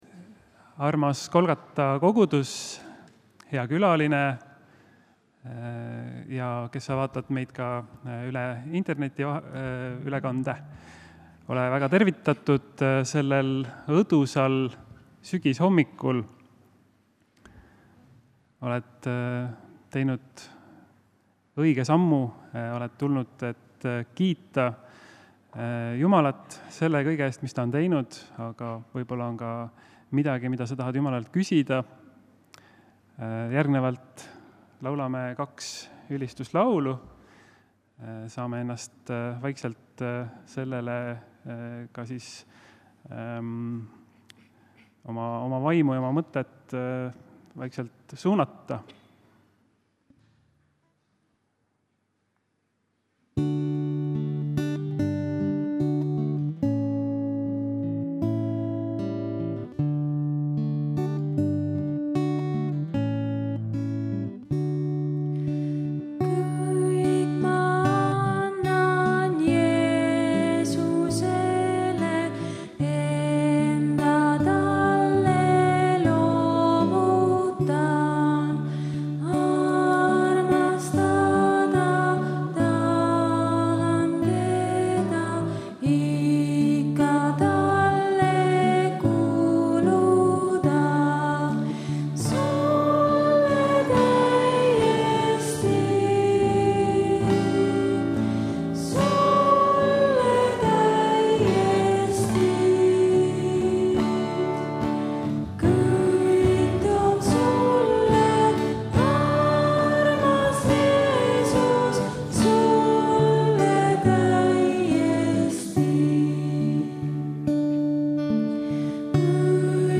Pühakirja lugemine
Jutlus